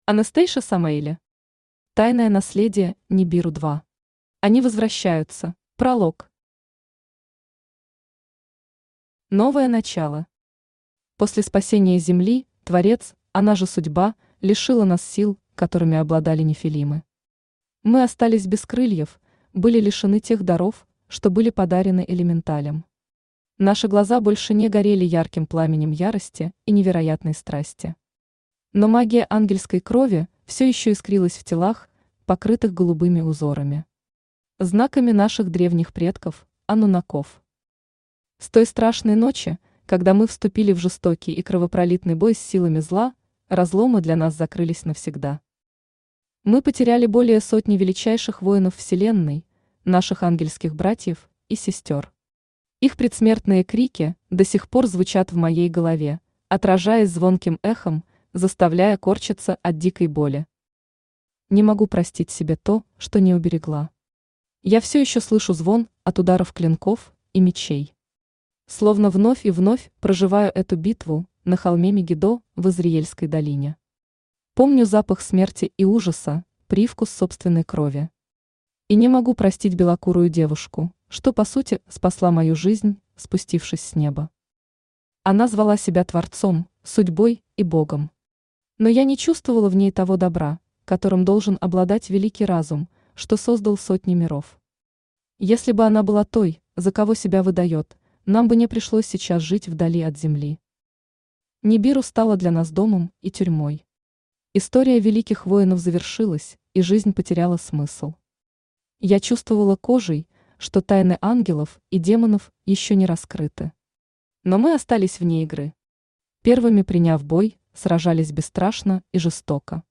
Аудиокнига Тайное наследие Нибиру 2. Они возвращаются | Библиотека аудиокниг
Они возвращаются Автор Anastasia Avi Samaeli Читает аудиокнигу Авточтец ЛитРес.